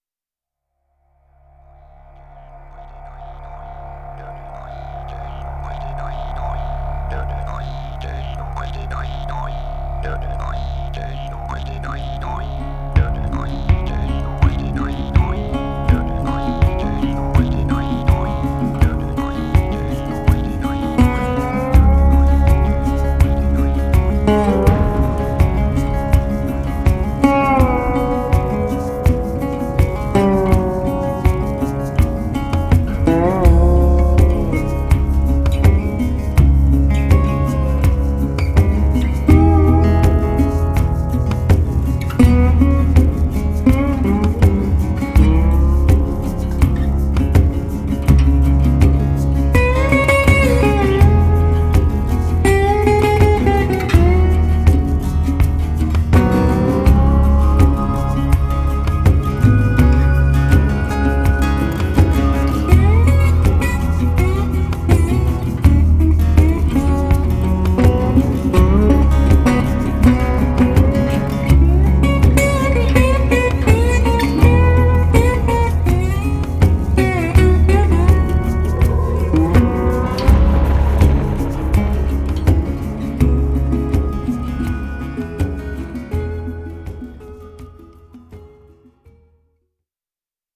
Swampy hypnotic backwoods cajun eerie instrumental.